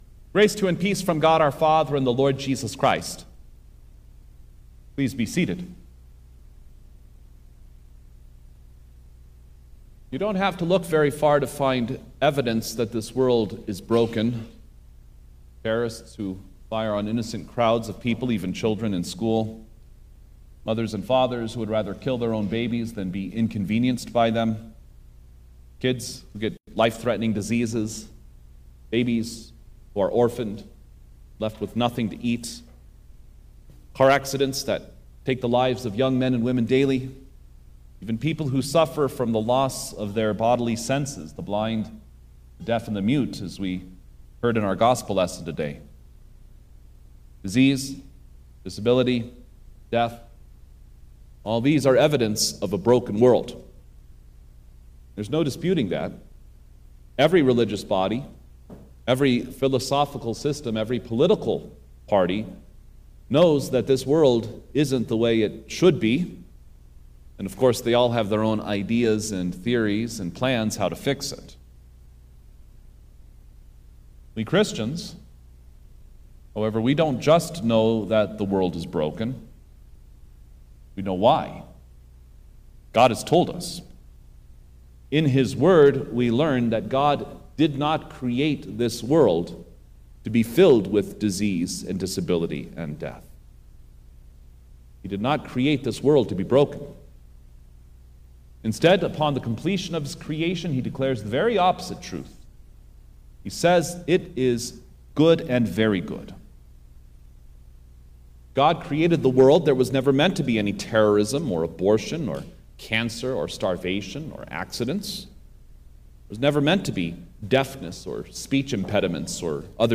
September-7_2025_Twelfth-Sunday-after-Trinity_Sermon_Stereo-Copy.mp3